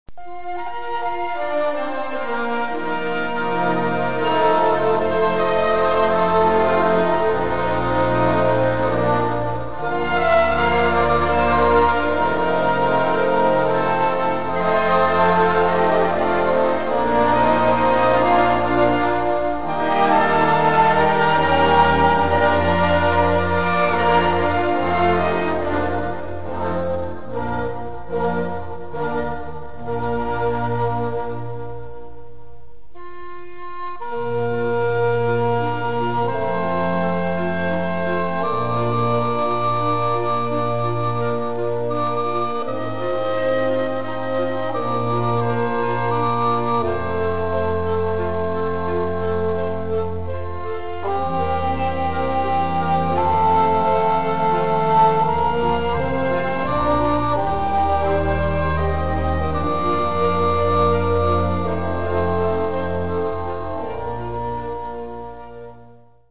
Gattung: Musik aus dem 18.Jhdt.
Besetzung: Blasorchester